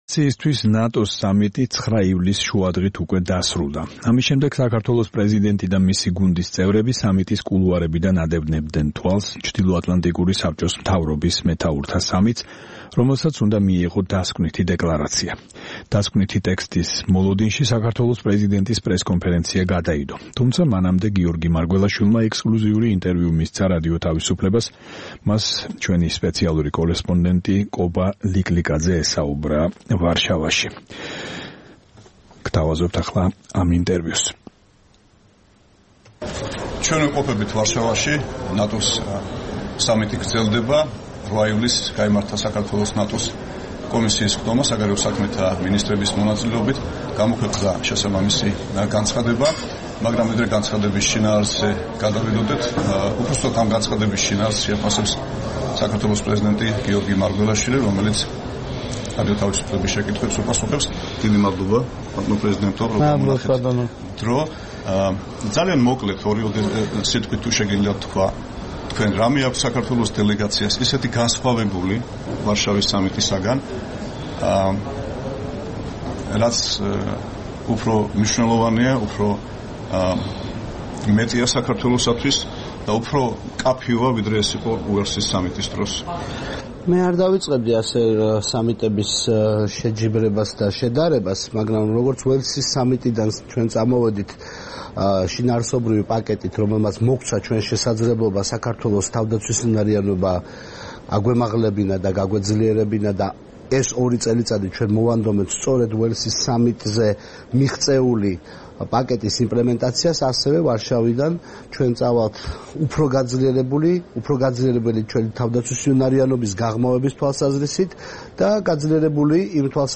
ინტერვიუ პრეზიდენტთან